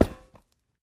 latest / assets / minecraft / sounds / block / vault / step6.ogg
step6.ogg